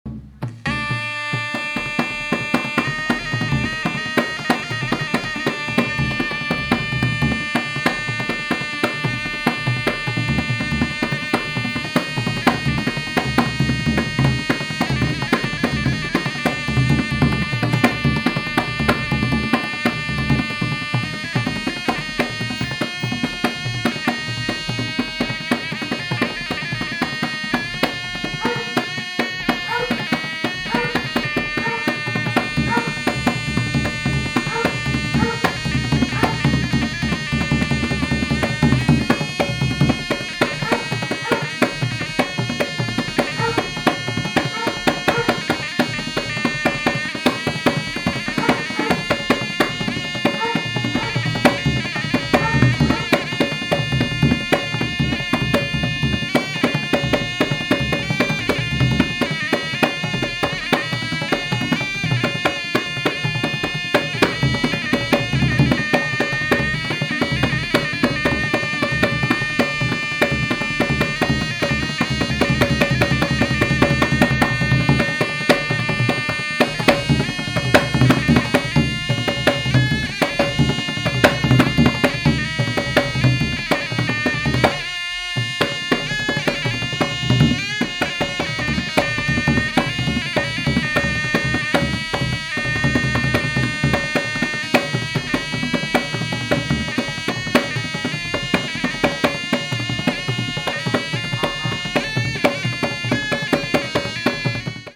弦楽の優雅な響きや幻想的なエレクトロニクスに、インドネシアの土着的な風合いを溶け合わせた傑作！